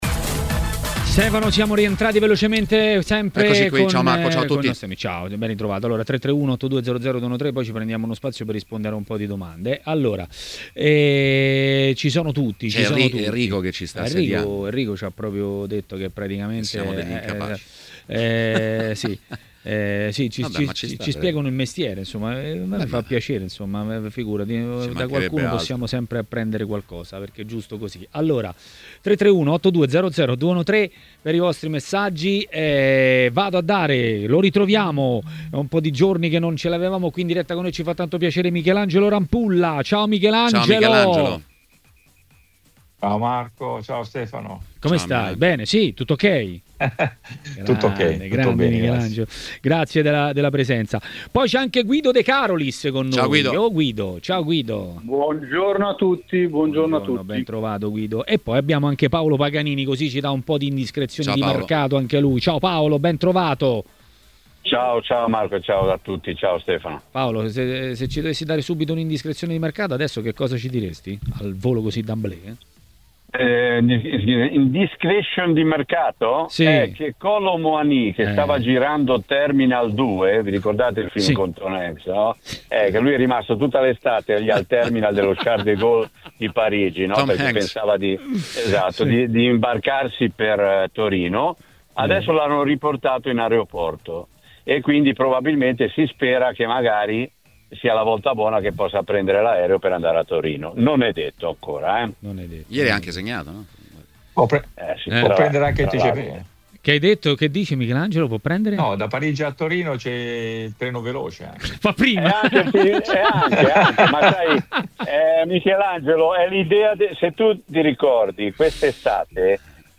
L'ex portiere Michelangelo Rampulla è intervenuto ai microfoni di TMW Radio, durante Maracanà.